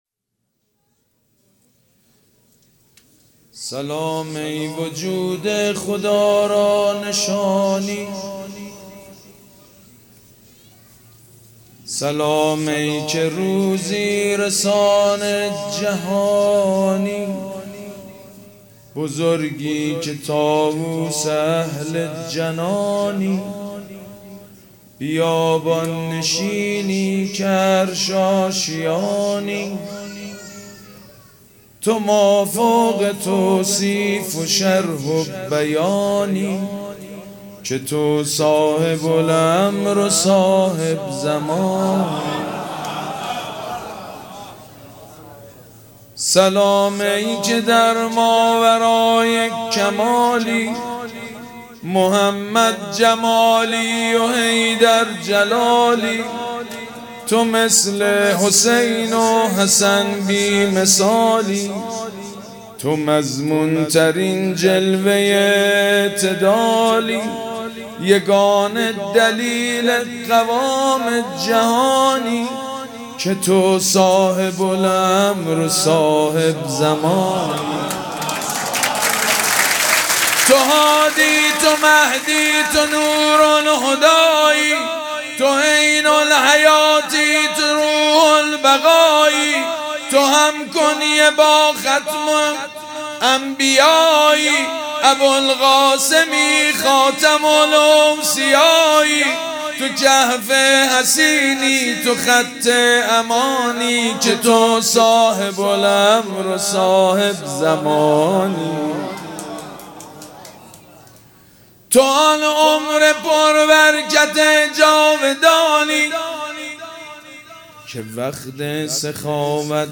مراسم جشن ولادت حضرت صاحب_الزمان (عج)
مدح
حاج سید مجید بنی فاطمه